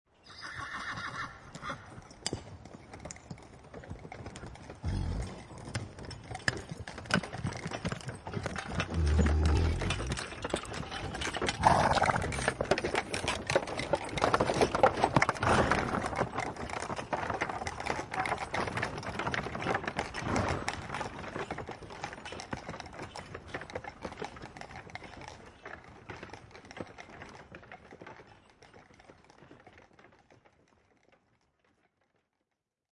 Horse With Old Cart Bouton sonore